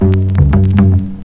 808s
Bass (10).wav